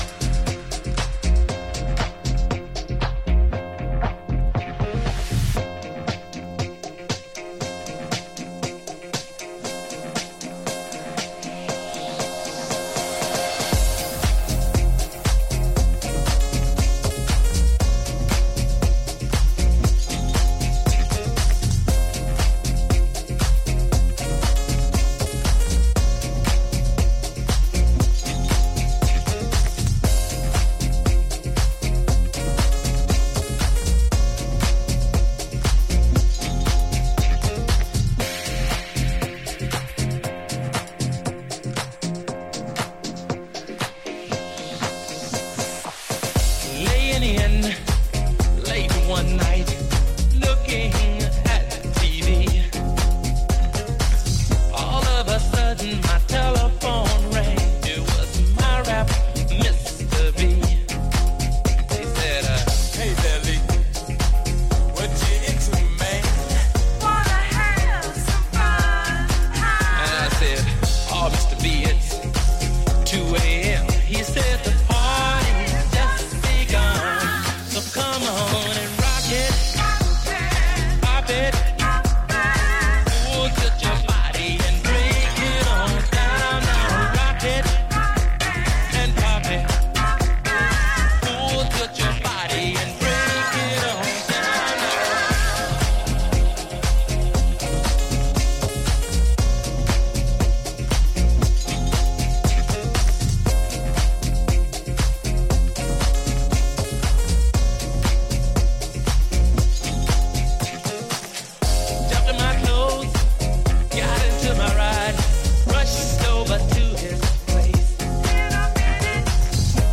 ジャンル(スタイル) DISCO / RE-EDIT